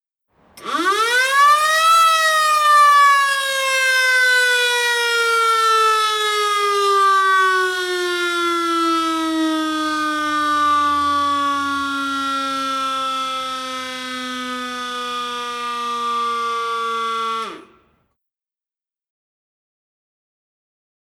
Звуки пожарной сирены, тревоги